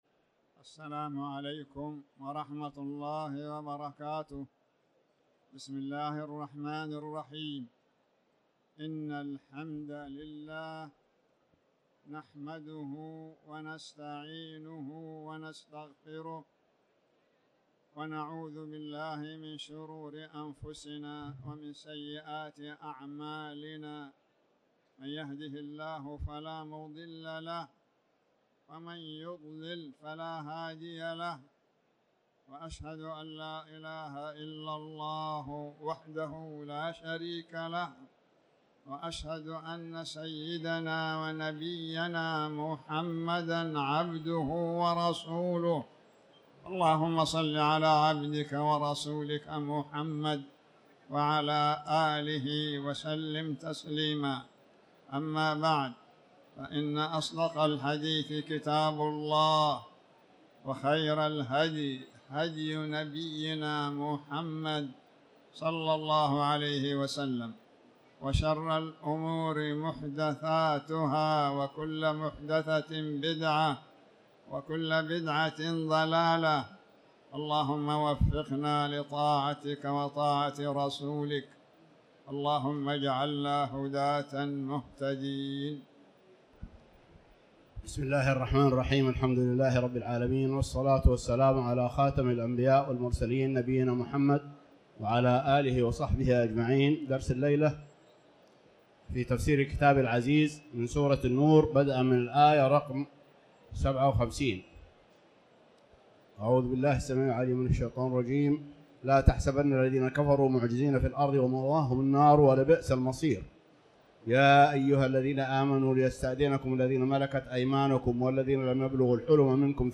تاريخ النشر ٢٩ جمادى الأولى ١٤٤٠ هـ المكان: المسجد الحرام الشيخ